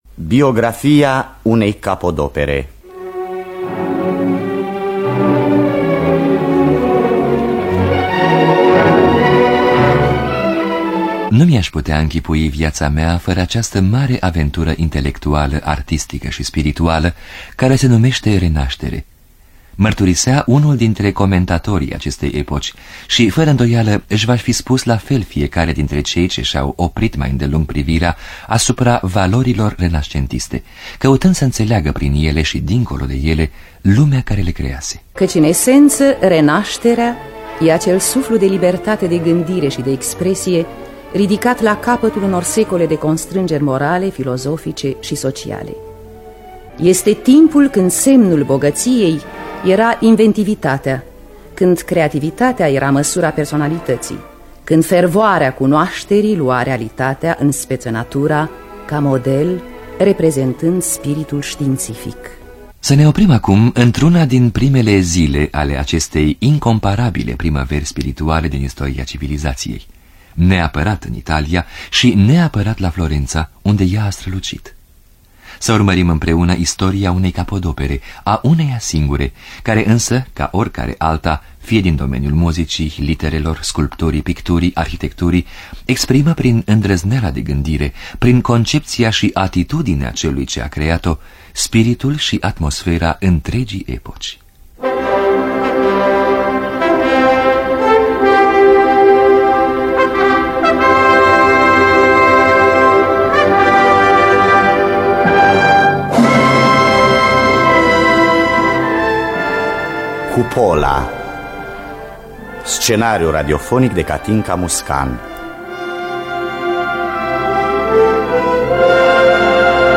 Biografii, memorii: Cupola lui Filippo Brunelleschi. Scenariu radiofonic